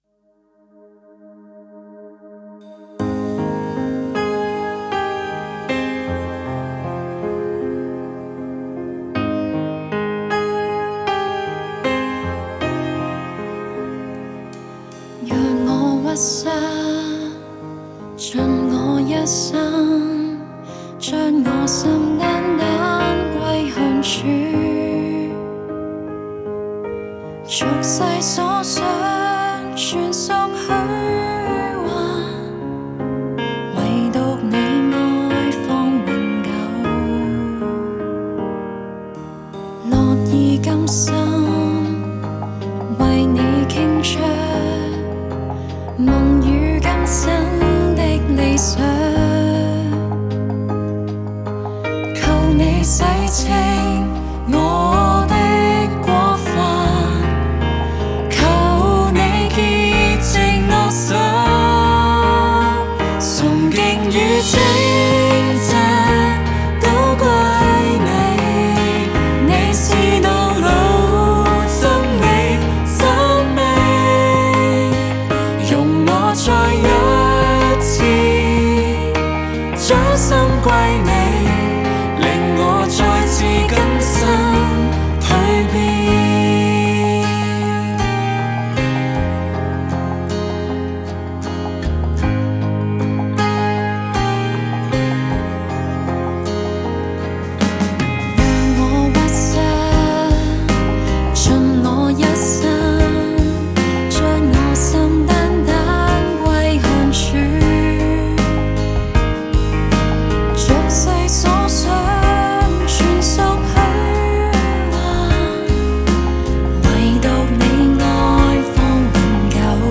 (原始和聲)